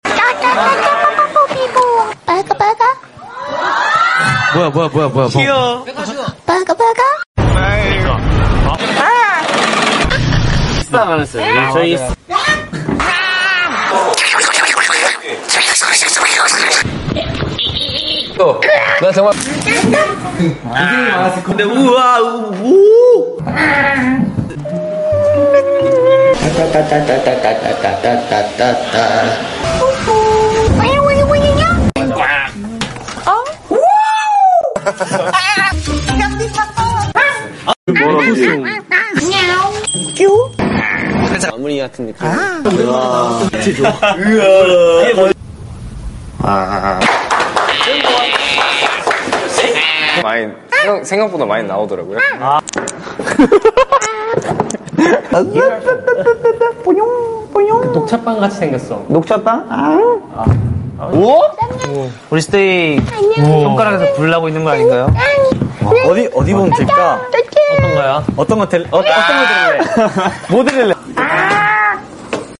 Han Jisung's tiny noises that sound effects free download
Han Jisung's tiny noises that can make your day better 🥰🫶🏻